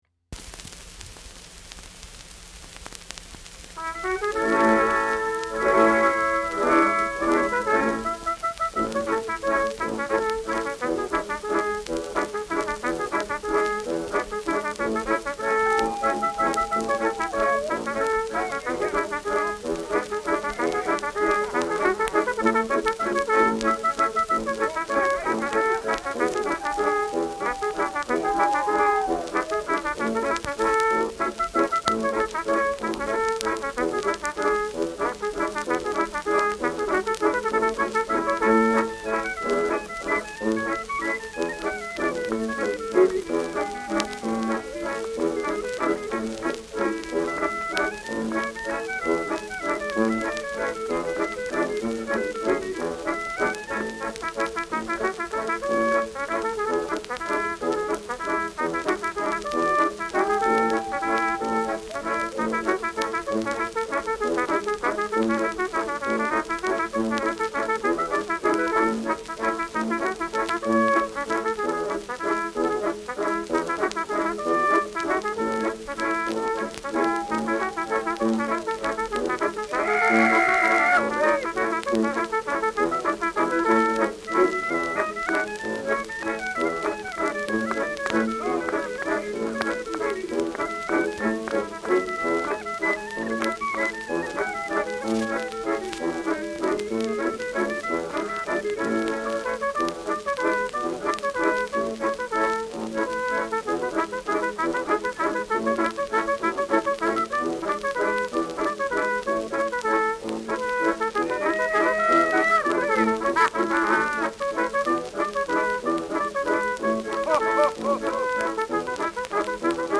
Schellackplatte
präsentes Knistern : leichtes Rauschen : leichtes Leiern
Dachauer Bauernkapelle (Interpretation)
Mit Juchzern und Zwischenrufen.